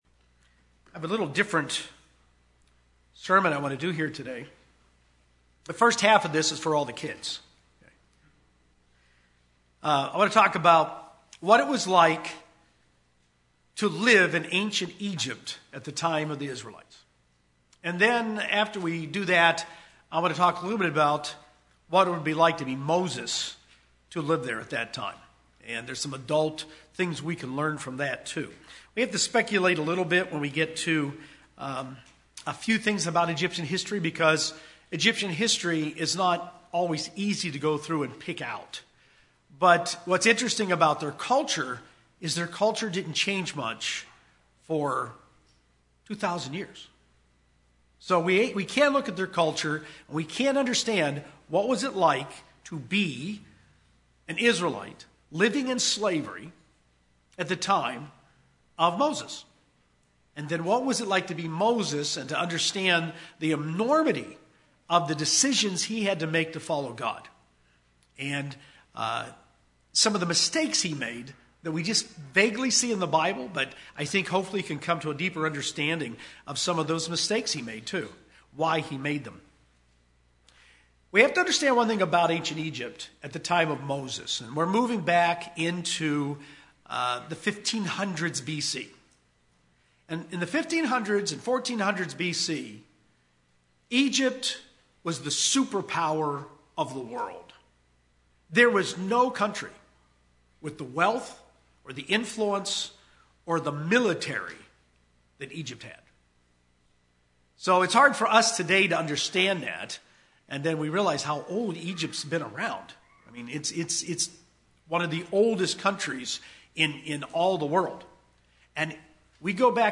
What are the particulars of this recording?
A closer look of when and by whom Moses was raised in Egypt amplifies the magnitude of his story. Also includes a picture of life in ancient Egypt as a slave. (This message was given on the First Day of Unleavened Bread, 2018).